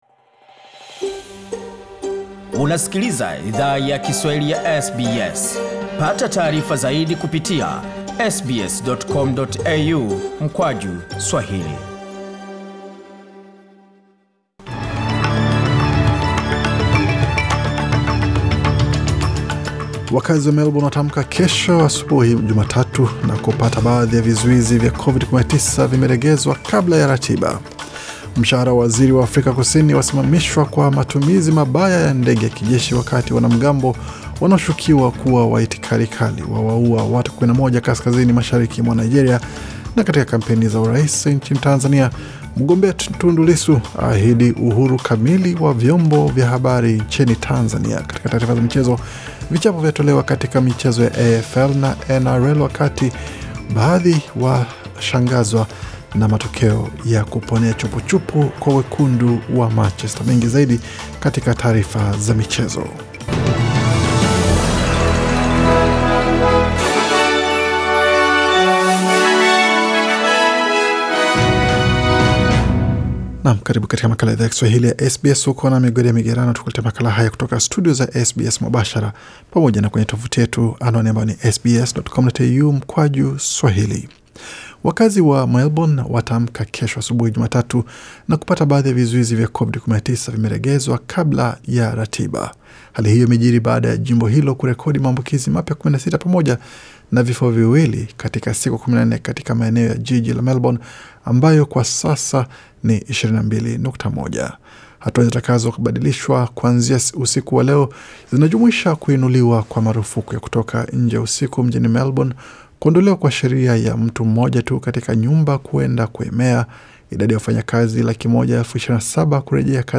Taarifa ya habari 27 Septemba 2020